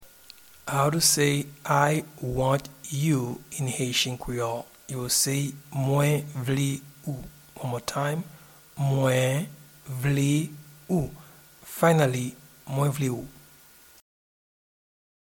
Pronunciation and Transcript:
I-want-you-in-Haitian-Creole-Mwen-vle-ou.mp3